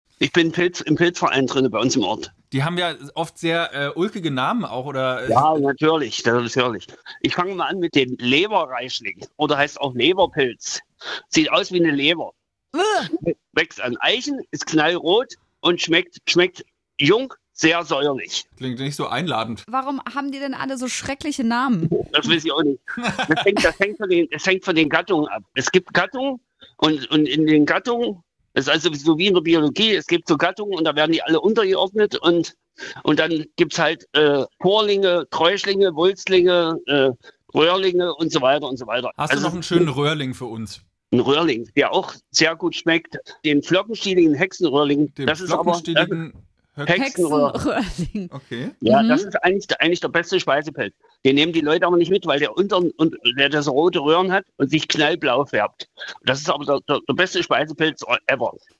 Der Pilz „sieht aus wie eine Leber, wächst an Eichen, knallrot und schmeckt jung sehr säuerlich“, sagt der Pilzsammler im Interview: